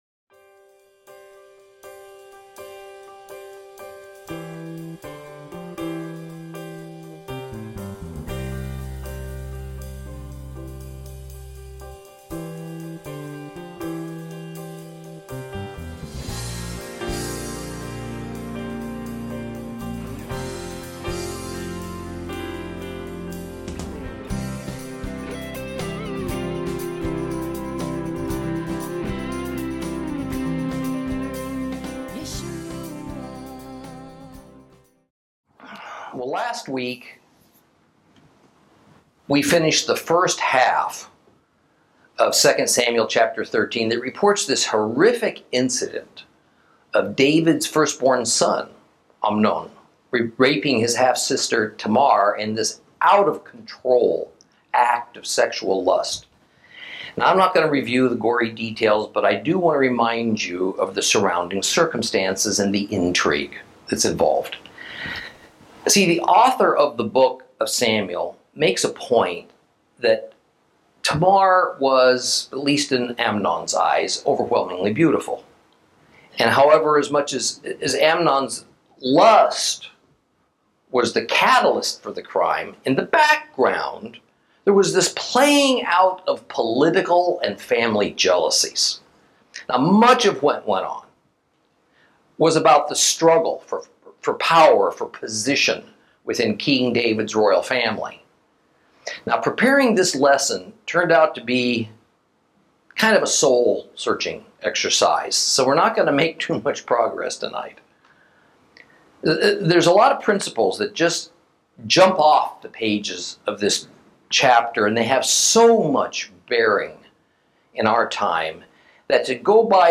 Lesson 21 Ch13 Ch14 - Torah Class